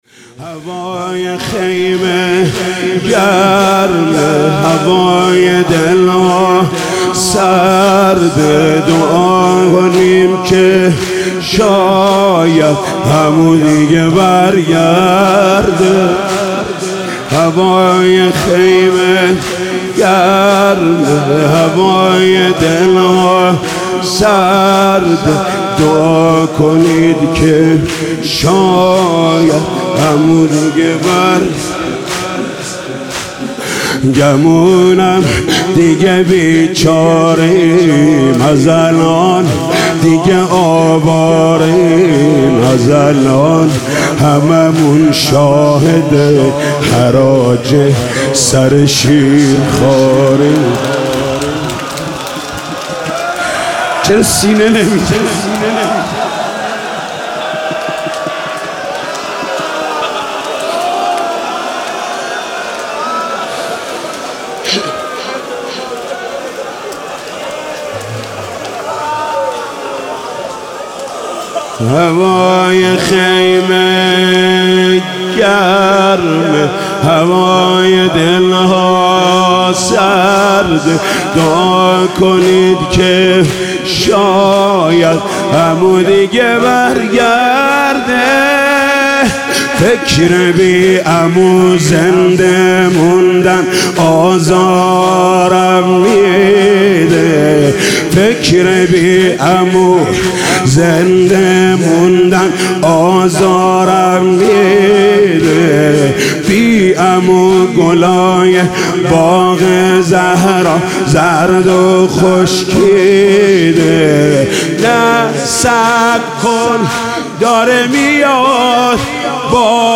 محرم 98 شب نهم - کریمی - زمینه - هوای خیمه گرمه